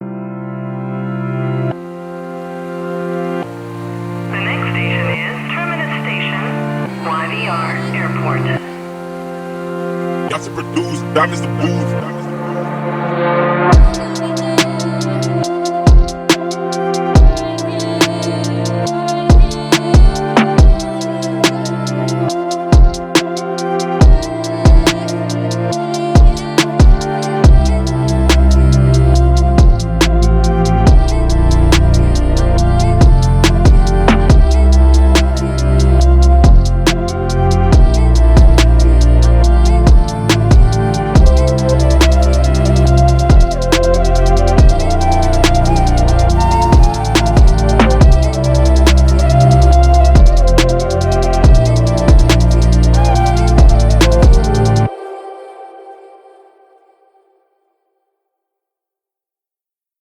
Hip Hop Instrumentals